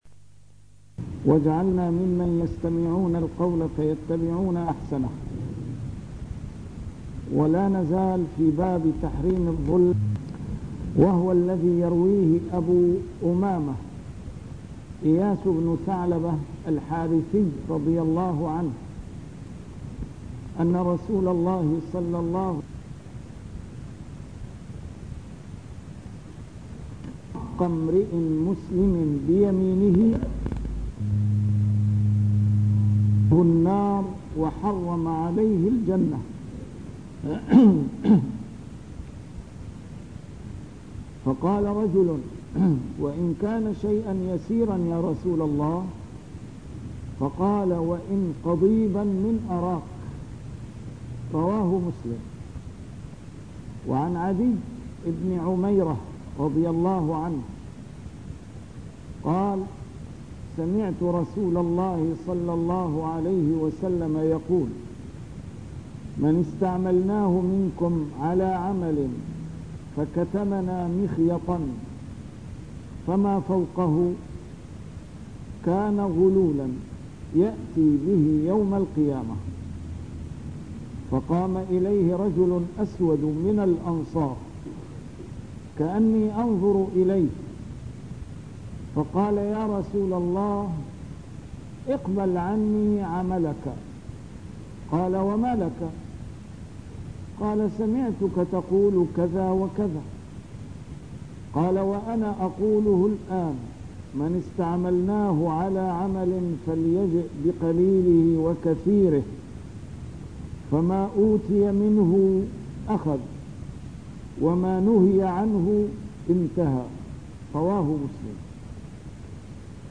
A MARTYR SCHOLAR: IMAM MUHAMMAD SAEED RAMADAN AL-BOUTI - الدروس العلمية - شرح كتاب رياض الصالحين - 323- شرح رياض الصالحين: تحريم الظلم